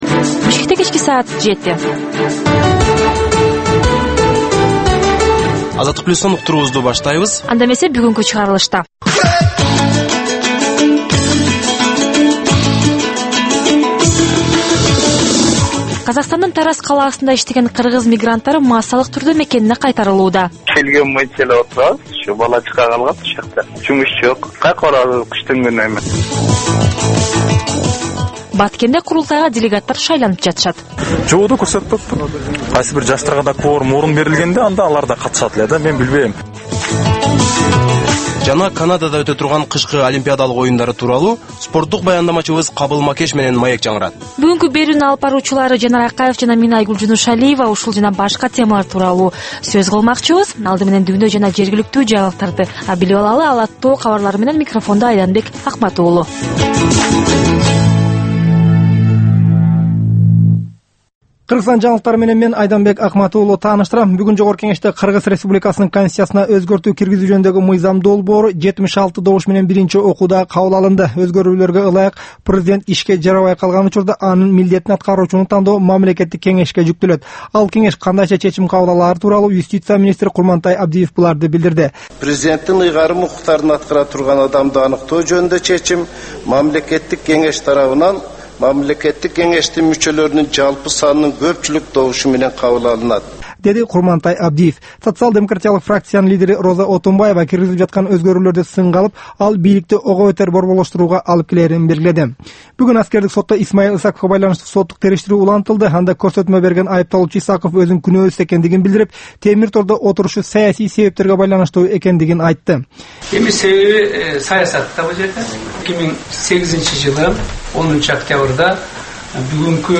"Азаттык үналгысынын" кыргызстандык жаштарга арналган бул кечки алгачкы үналгы берүүсү жергиликтүү жана эл аралык кабарлардан, репортаж, маек, баян жана башка берүүлөрдөн турат.